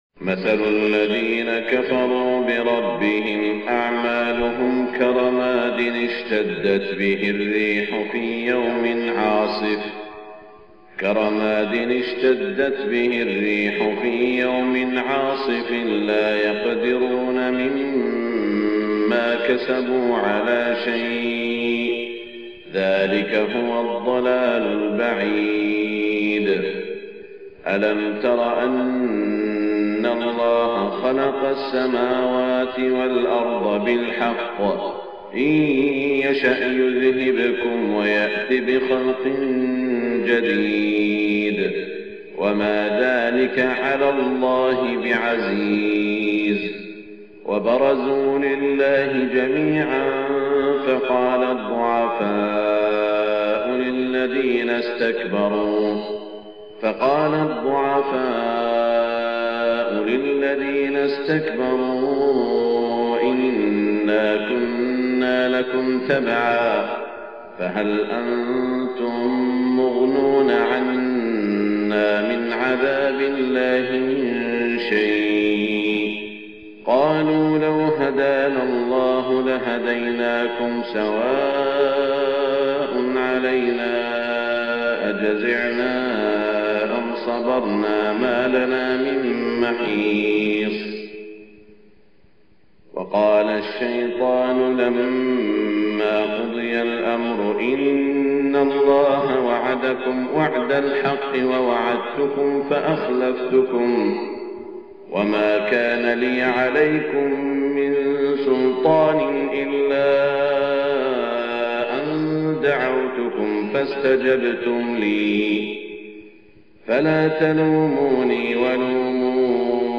صلاة الفجر 17 ذو القعدة 1427هـ من سورة إبراهيم > 1427 🕋 > الفروض - تلاوات الحرمين